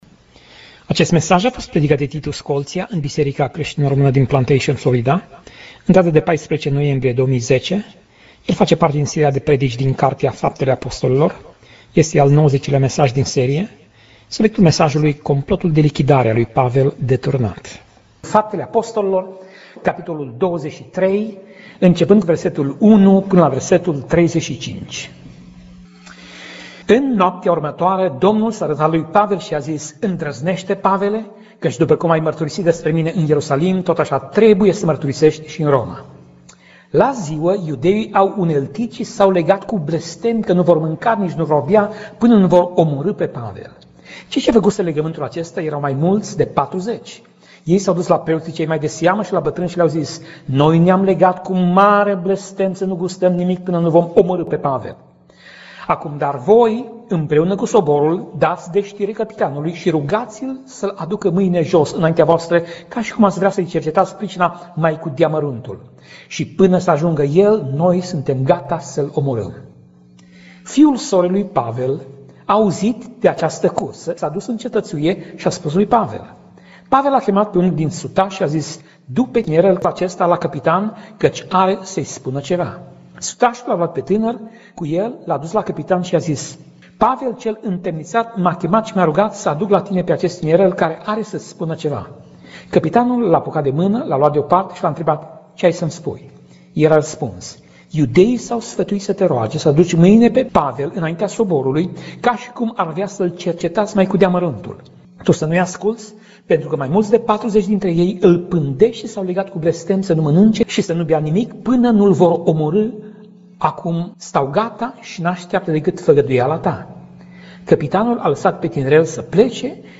Pasaj Biblie: Faptele Apostolilor 23:11 - Faptele Apostolilor 23:35 Tip Mesaj: Predica